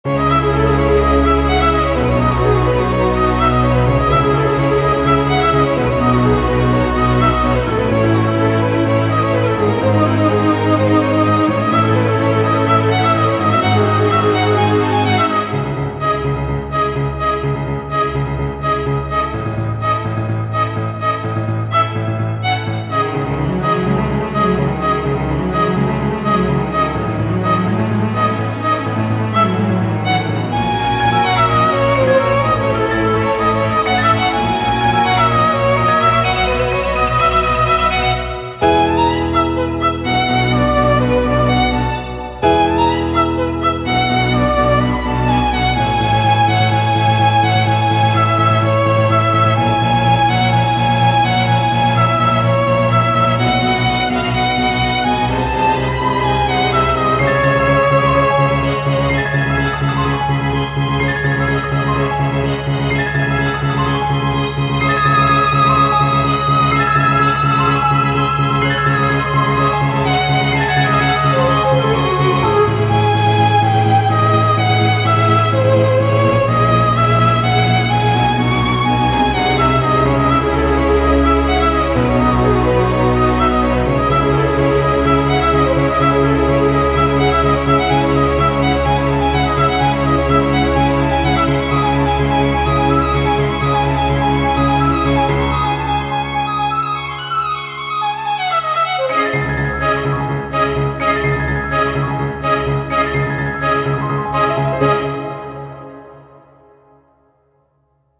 -oggをループ化-   ゴシック 弦楽 1:53 mp3